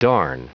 Prononciation du mot darn en anglais (fichier audio)
Prononciation du mot : darn